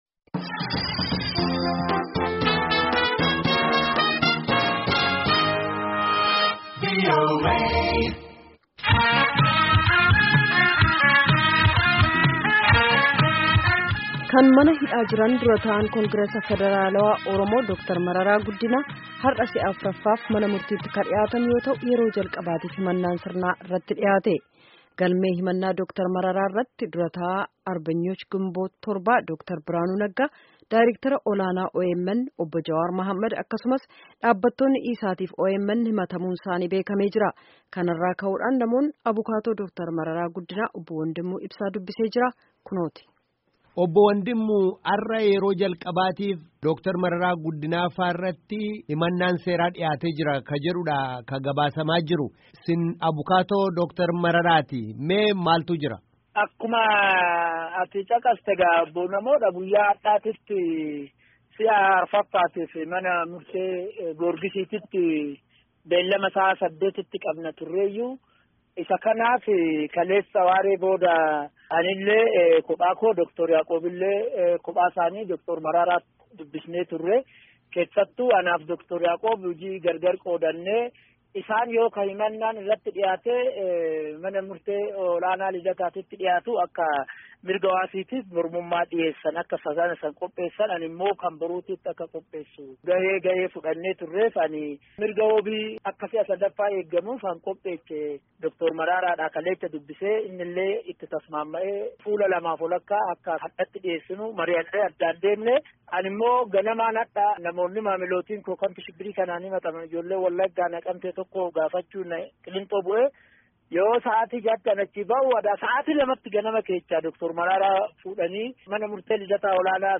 Gaaffii fi deebii guutuu